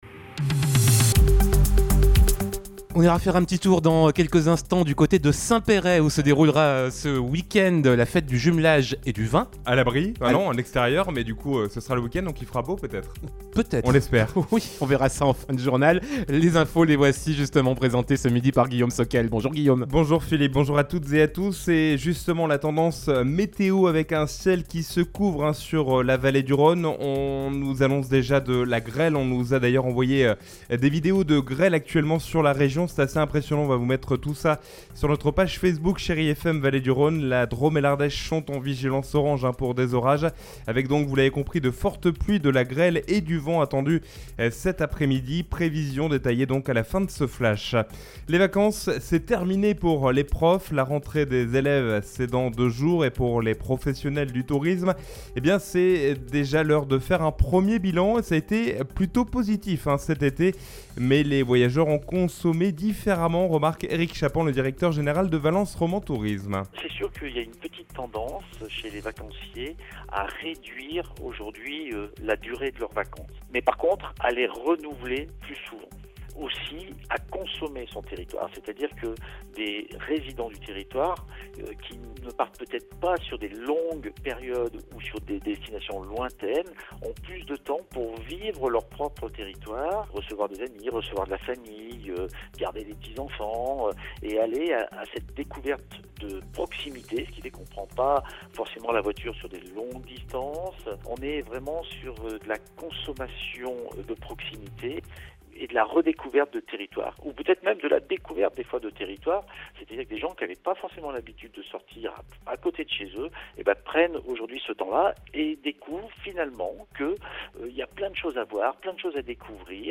Mardi 30 août : Le journal de midi